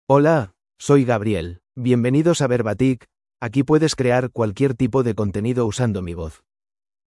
MaleSpanish (Spain)
GabrielMale Spanish AI voice
Voice sample
Listen to Gabriel's male Spanish voice.
Gabriel delivers clear pronunciation with authentic Spain Spanish intonation, making your content sound professionally produced.